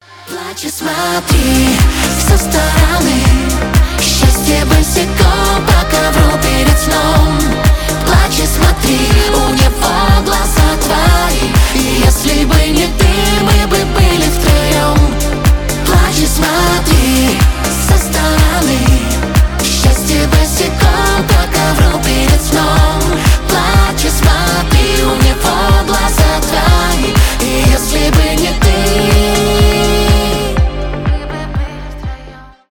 поп
дуэт